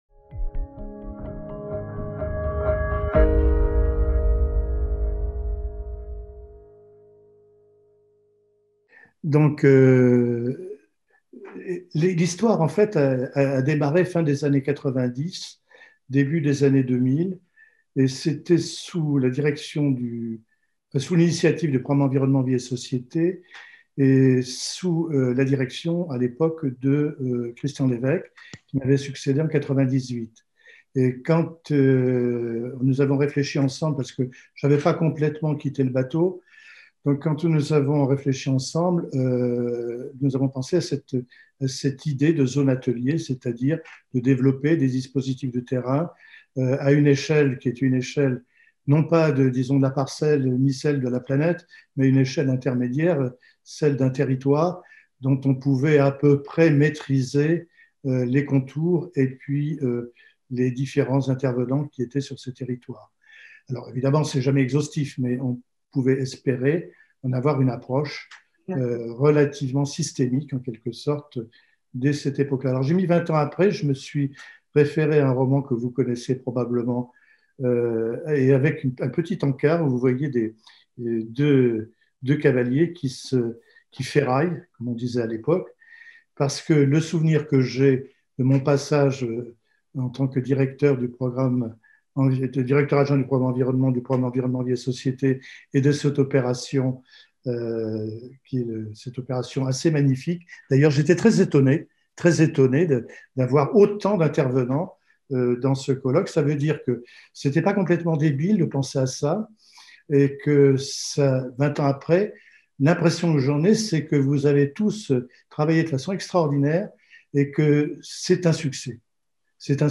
Conférence introductive - Pourquoi des zones ateliers ? Retour sur les origines du concept | Canal U